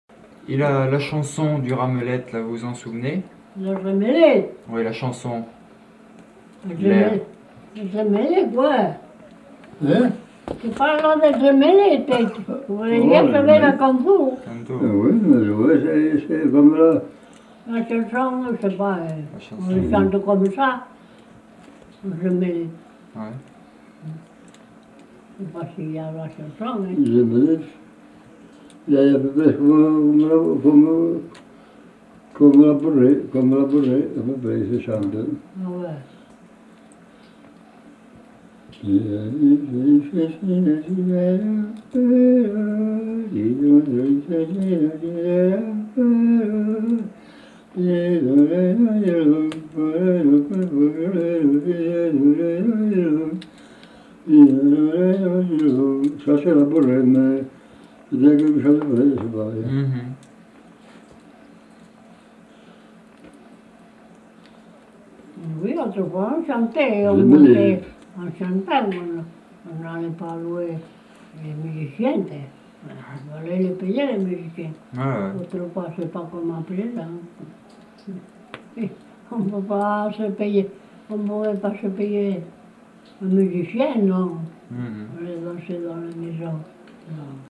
Aire culturelle : Couserans
Genre : chant
Effectif : 1
Type de voix : voix d'homme
Production du son : fredonné
Danse : bourrée d'Ariège